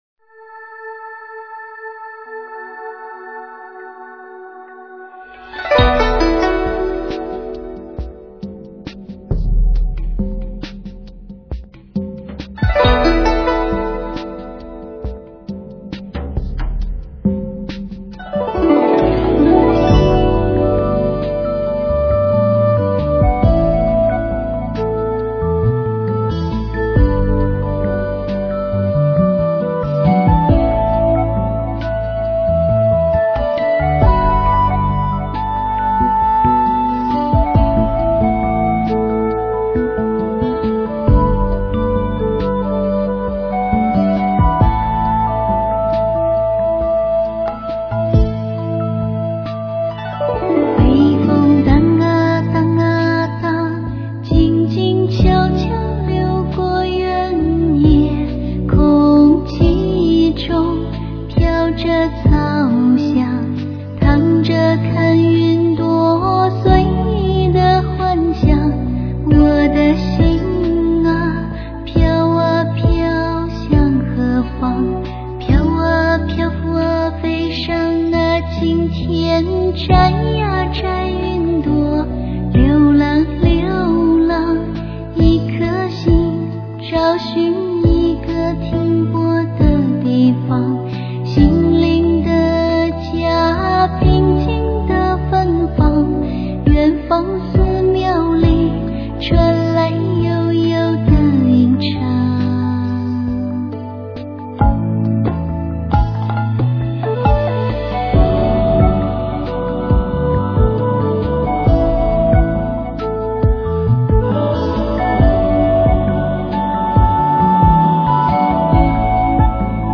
最动听的佛教音乐，福佑尘世凡人，
水晶般纯净的女声，造福众生的佛颂，
清幽抒情的旋律，时尚清闲的编配，
让您的心增色自在宁静，神游情动，清新舒畅，